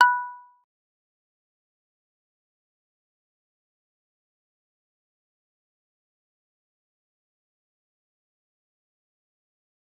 G_Kalimba-B6-mf.wav